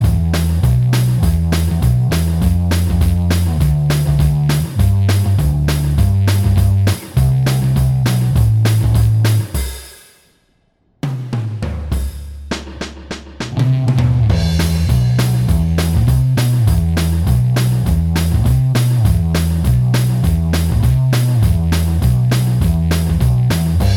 Minus Guitars Rock 2:49 Buy £1.50